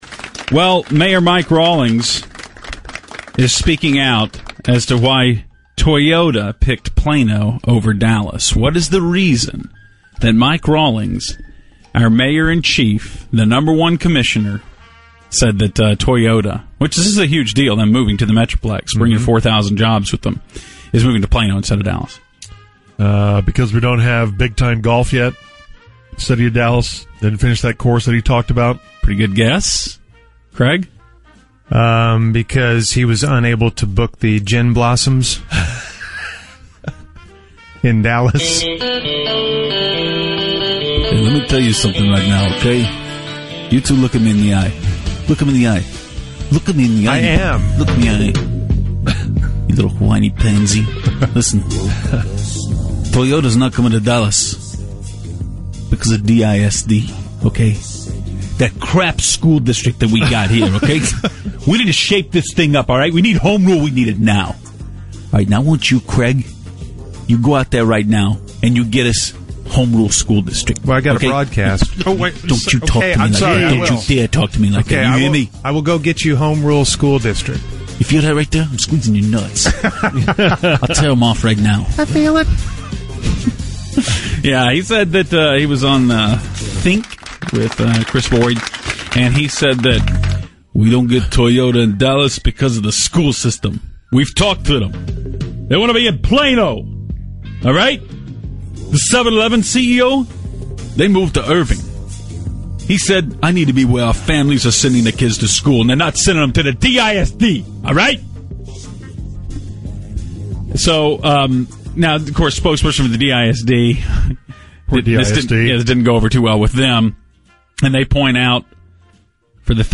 Mayor Mike Rawlings explains why Toyota is moving to Plano instead of Dallas (Fake Mike Rawlings)